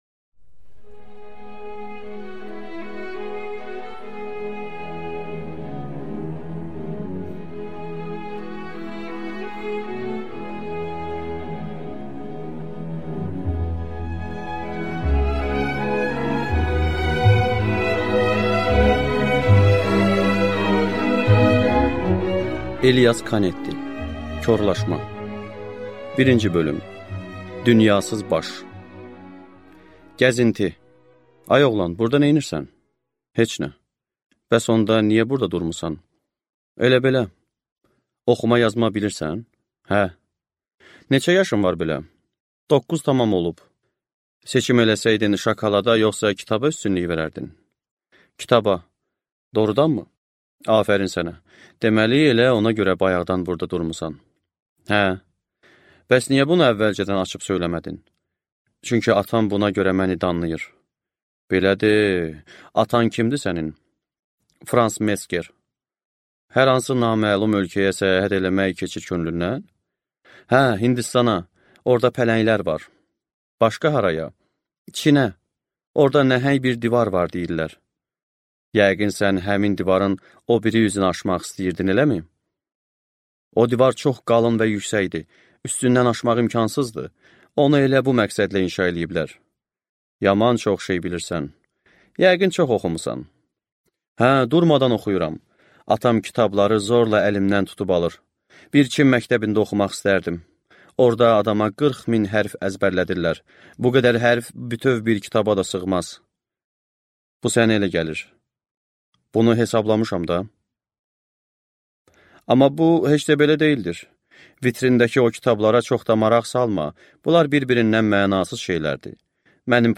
Аудиокнига Korlaşma 1-ci hissə | Библиотека аудиокниг